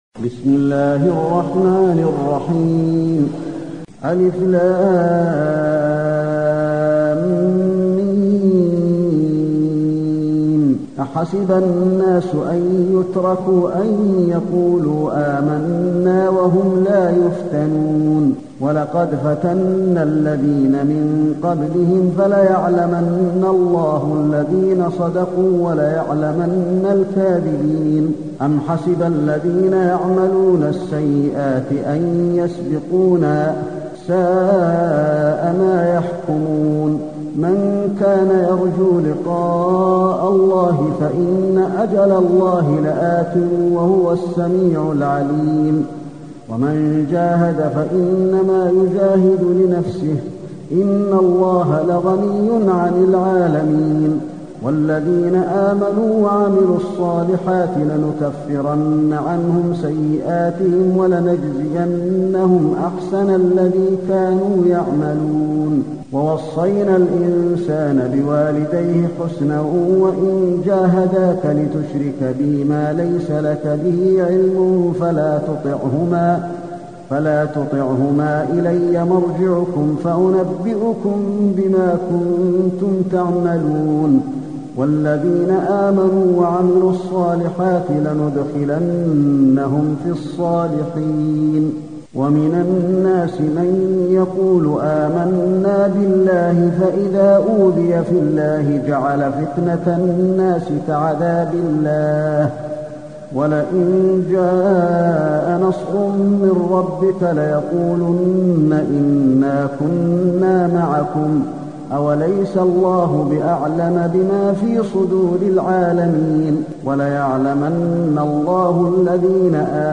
المكان: المسجد النبوي العنكبوت